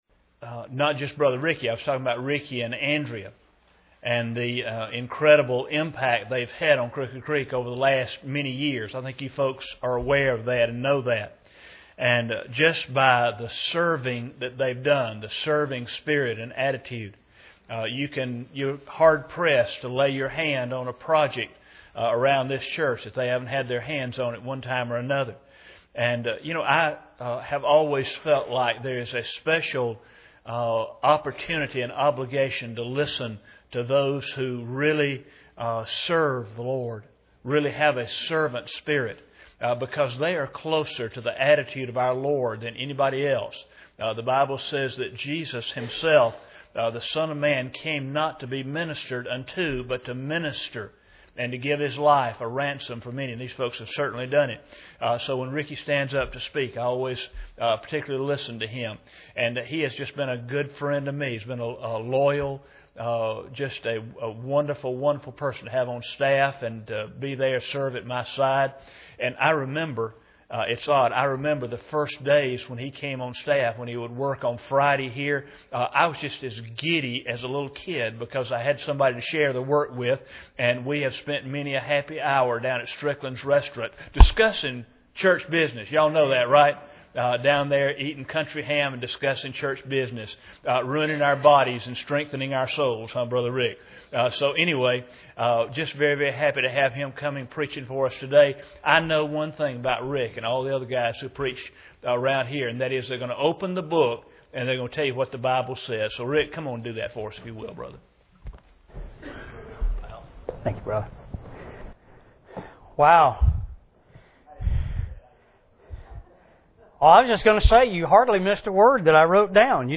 Ephesians 5:14-20 Service Type: Sunday Evening Bible Text